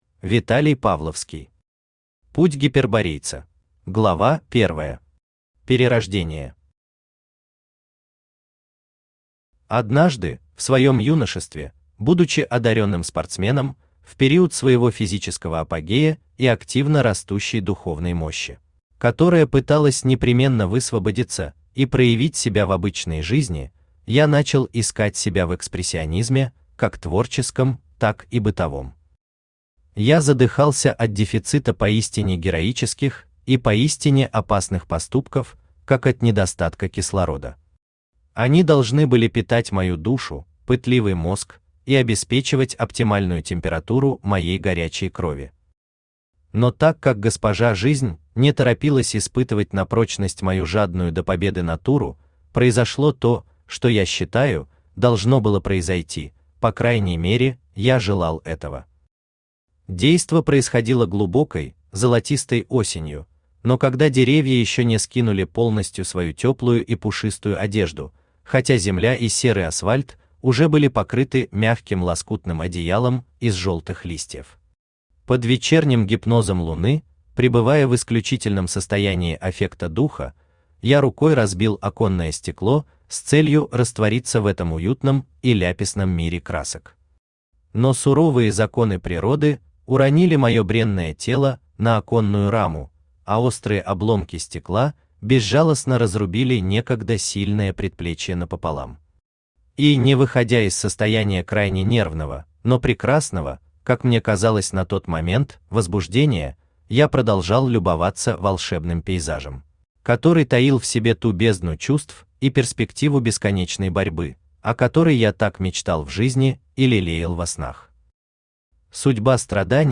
Аудиокнига Путь Гиперборейца | Библиотека аудиокниг
Aудиокнига Путь Гиперборейца Автор Виталий Олегович Павловский Читает аудиокнигу Авточтец ЛитРес.